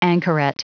Prononciation du mot anchoret en anglais (fichier audio)
Prononciation du mot : anchoret